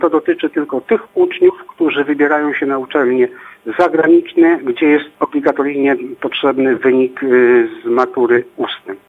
Wojciech Cybulski, wicekurator oświaty w Olsztynie tłumaczy, że dotyczy to tylko wąskiego grona osób.